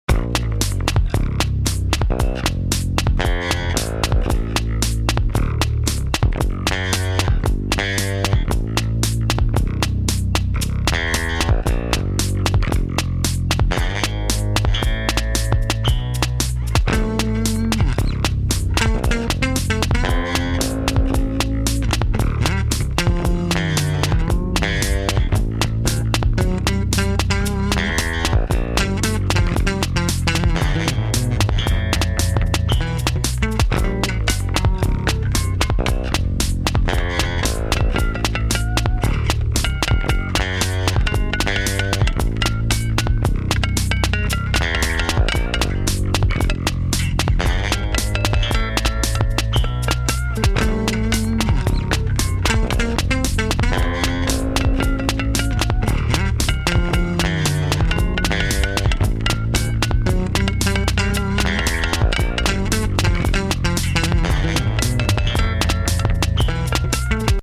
home of the daily improvised booty and machines -
orginal 3 bass grooves